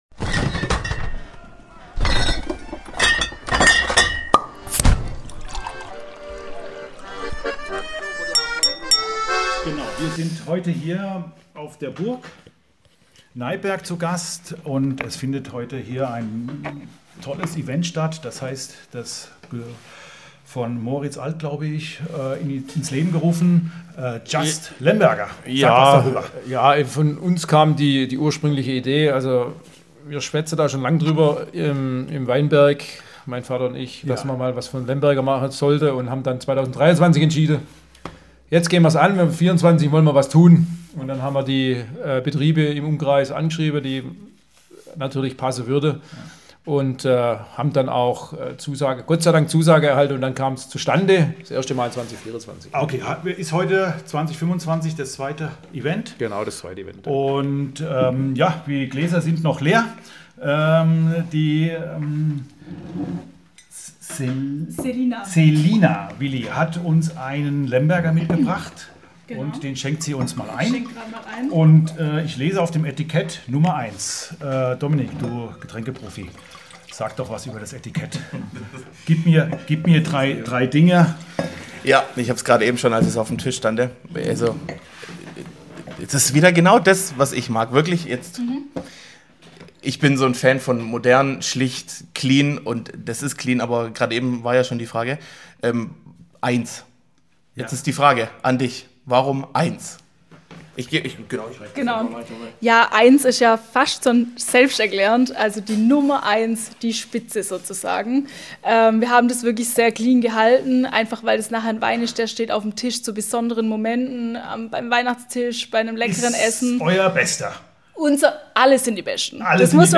Beschreibung vor 1 Jahr In dieser Folge dreht sich alles um eine einzige Rebsorte: Lemberger - und zwar trocken! Wir waren auf der exklusiven Verkostung „just Lemberger" auf der Burg Neipperg und haben uns durch spannende Interpretationen dieser charakterstarken Rebsorte probiert.
Besonders spannend: Wir hatten viele Winzer direkt am Mikrofon und konnten mit ihnen über Stilistik, Herkunft und Zukunft des Lembergers sprechen.